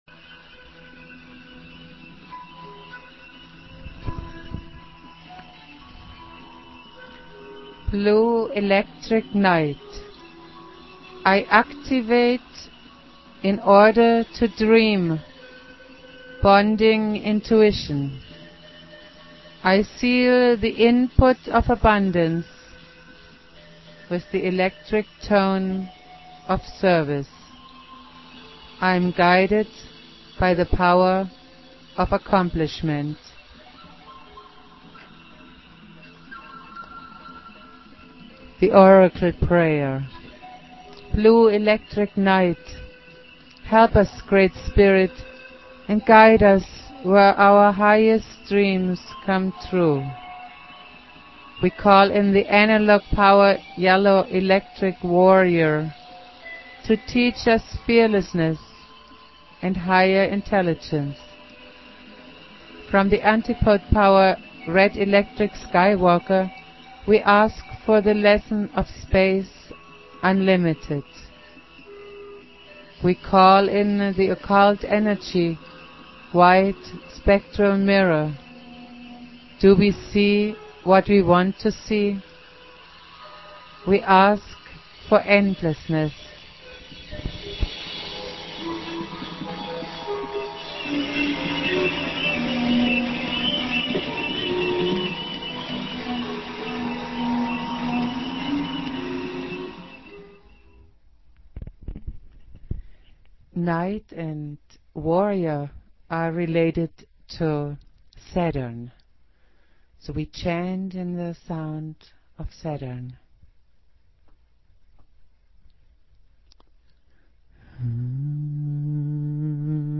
Prayer
Jose's spirit and teachings go on Jose Argüelles playing flute.